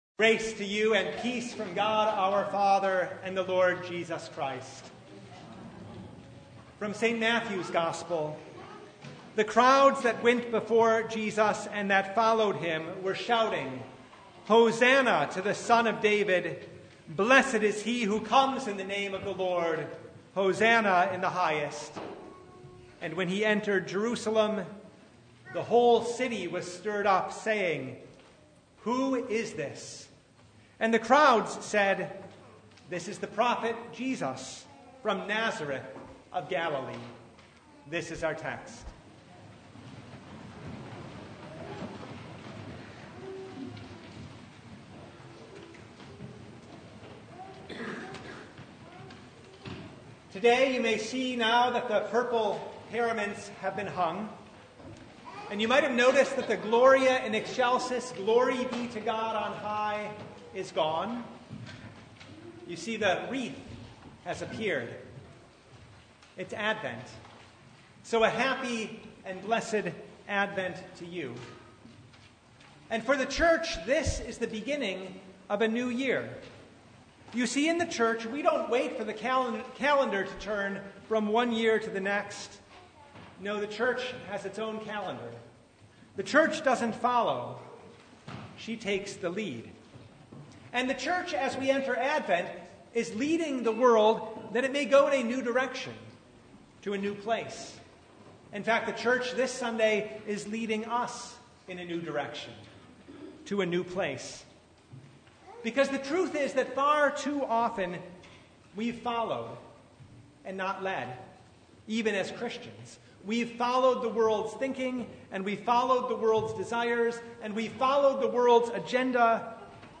Service Type: Advent